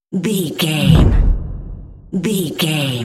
Dramatic hit deep fast trailer
Sound Effects
Atonal
Fast
heavy
intense
dark
aggressive
hits